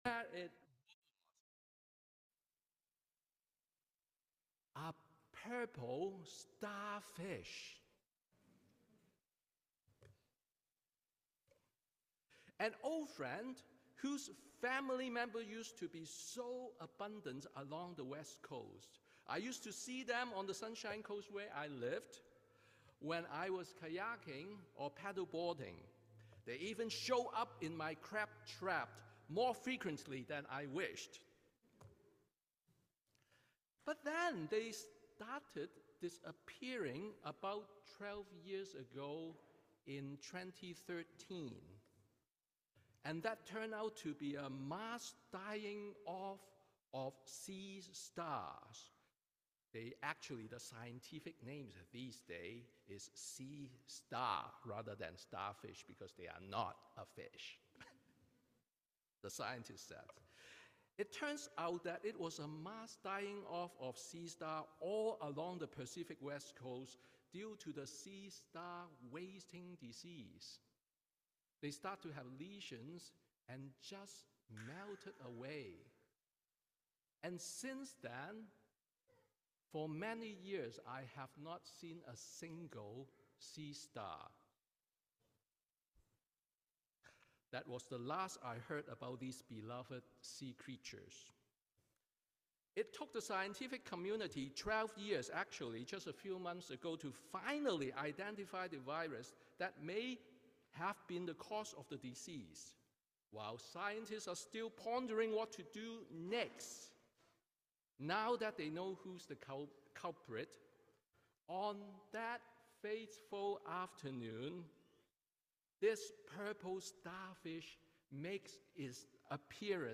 Sermon on First Sunday of Creationtide